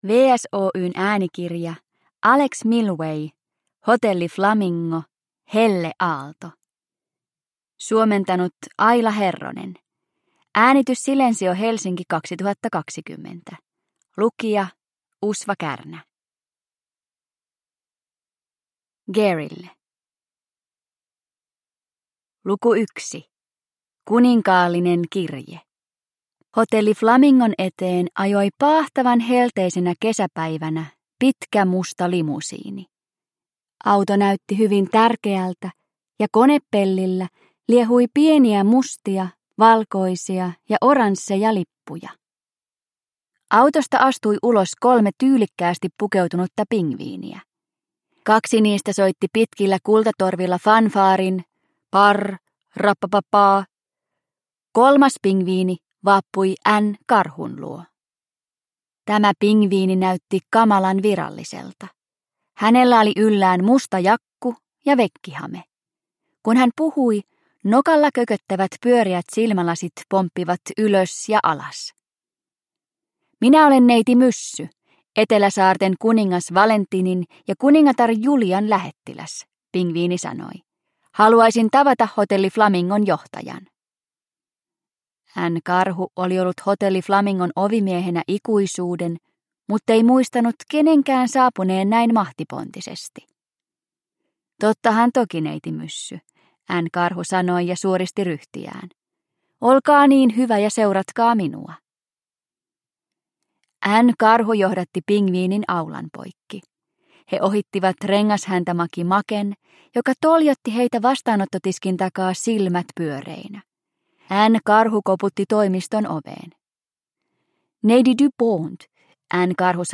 Hotelli Flamingo: Helleaalto – Ljudbok – Laddas ner